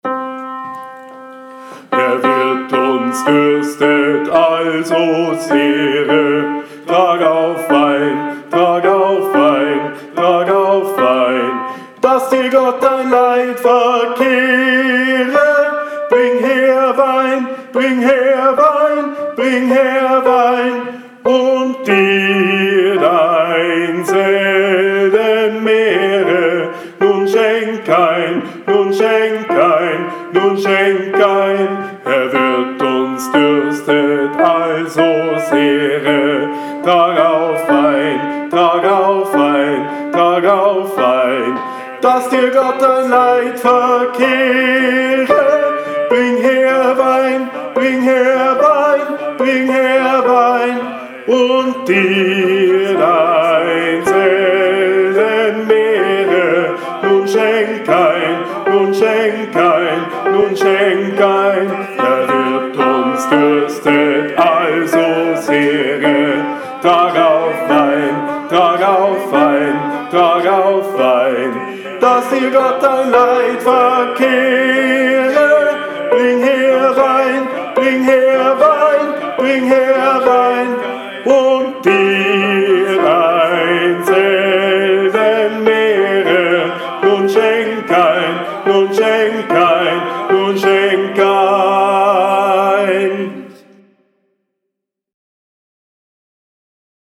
23 Herr wirt uns durstet Kanon STIMME 1 mitte.mp3